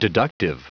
Prononciation du mot deductive en anglais (fichier audio)
Prononciation du mot : deductive